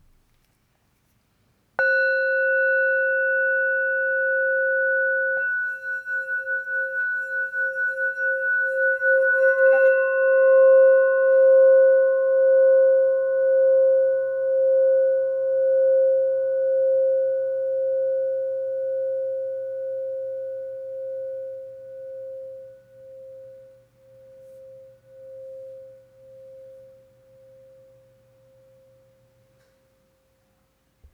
C Note Flower of Life Singing Bowl